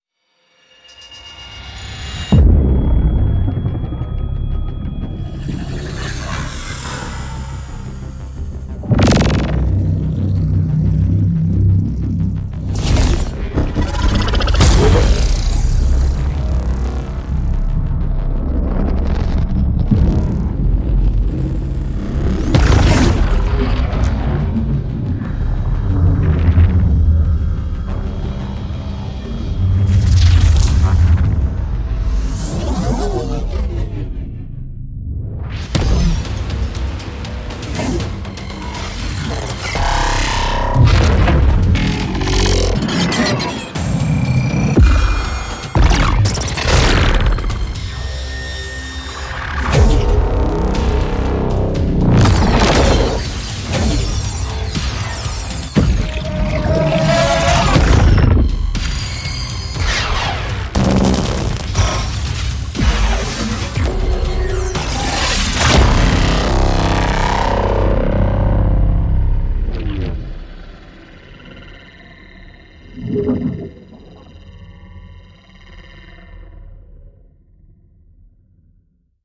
爆炸，BRAAMS，命中，动作，粒子，飞快移动，纹理，ZAPS，氛围
在您的项目中添加这些巨大的冲击力，巧妙的打击声，动人的动听声音和惊人的声音，无论您是否喜欢，都将使它们听起来像是尖端的。
我们同时使用硬件和软件合成器，并在不断增长的模块化合成器上花费了大量时间来创建最先进的声音。我们还花费大量时间记录有机材料，例如金属屑，鼓撞击，临时制造的乐器等，以便能够将它们重新放入合成和加工链中。
另一方面，NEON本身的声音保持效果非常好，可为您的视觉效果增添独特的能量和科幻特征。